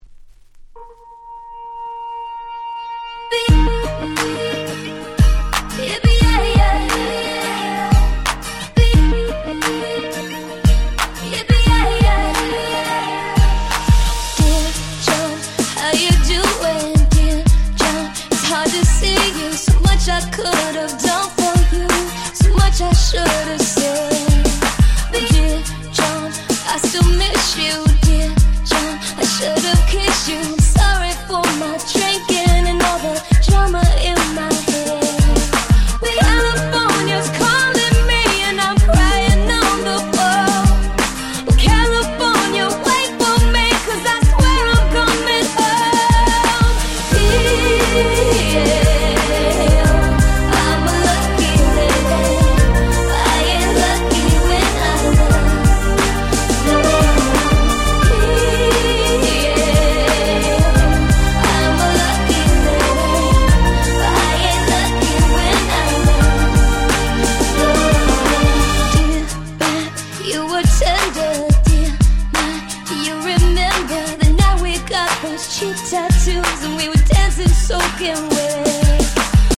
08' Nice EU R&B !!
キャッチー系